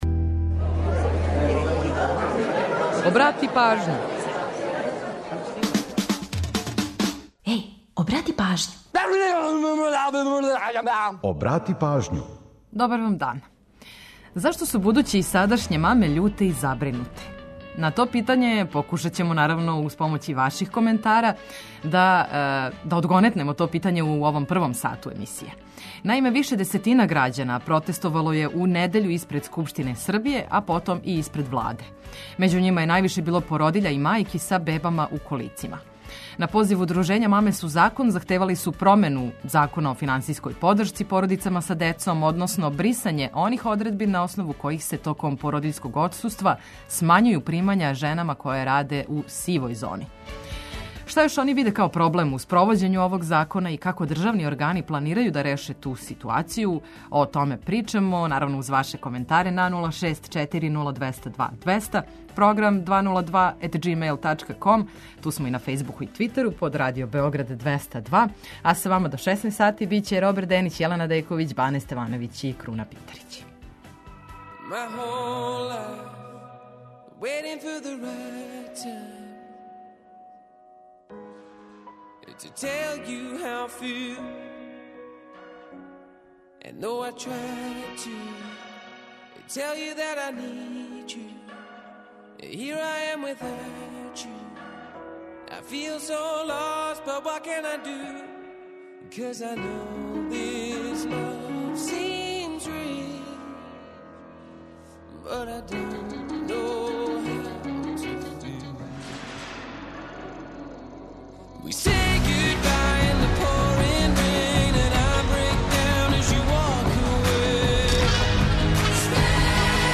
Čućete i „Priče o pesmama”, pola sata muzike iz Srbije i regiona, a naš reporter upozorava na eventualne saobraćajne gužve na gradskim ulicama.